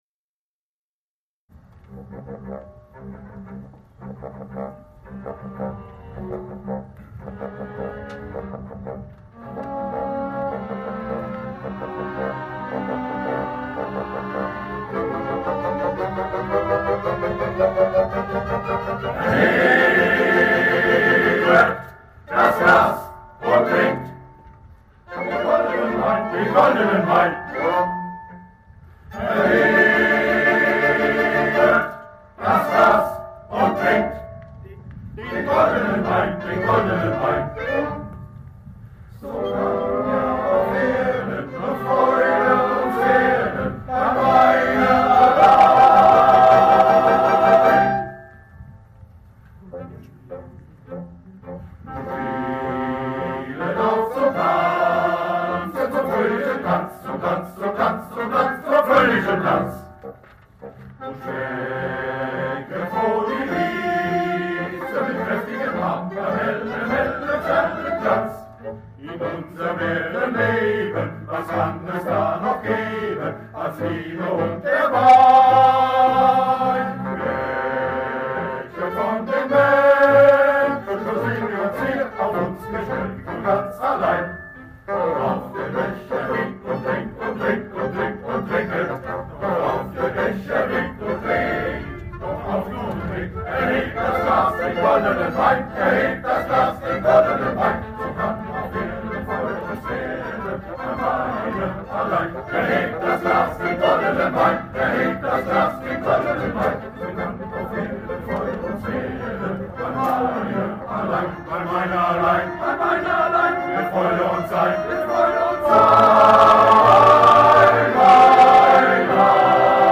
Wallufer Männerchöre